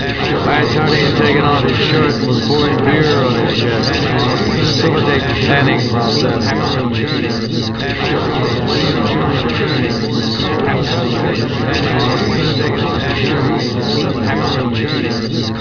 In the first, short speech recordings are obscured by a noise which is meant to resemble that in a crowded room with several people talking at the same time.
• In all examples, the speech consists of (slightly slurred) American English, spoken by a man
• All sound files are in the  .wav format (mono)
Part 1: Obscured speech